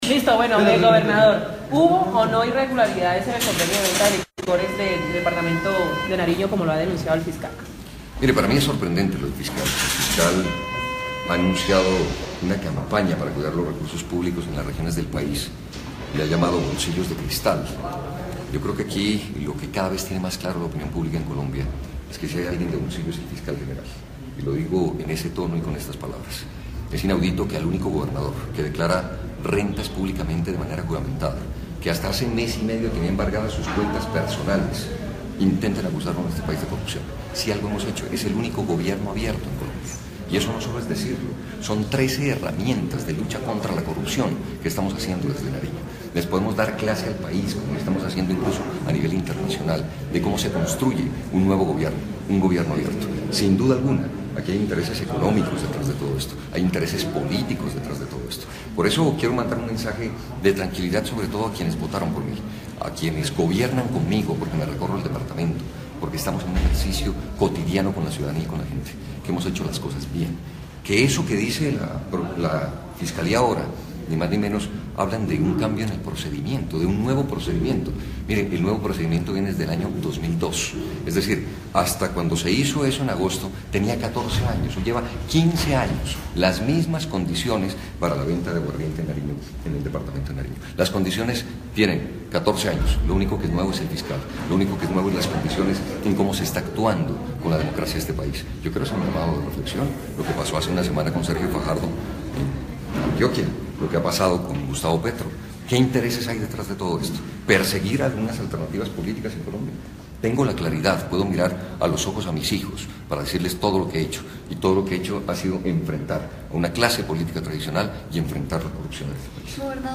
Rueda de Prensa Gobernador Camilo Romero Su navegador no soporta AUDIO.